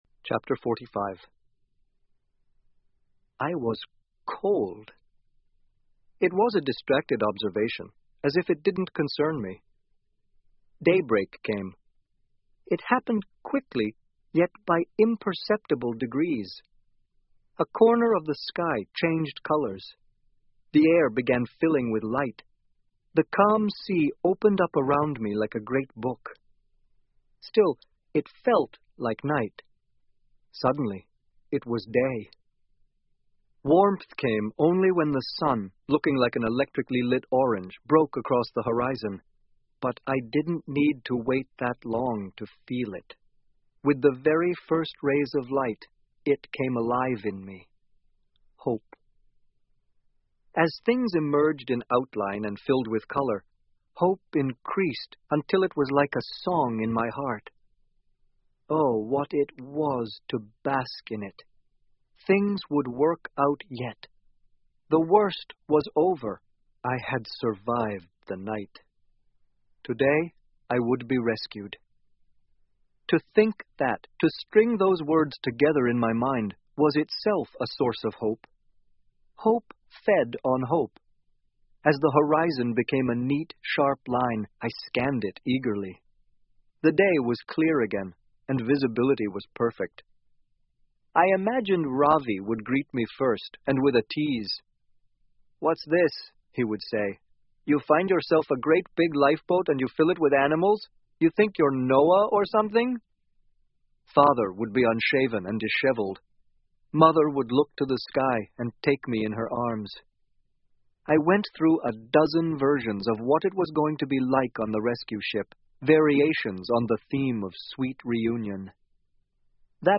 英文广播剧在线听 Life Of Pi 少年Pi的奇幻漂流 04-04 听力文件下载—在线英语听力室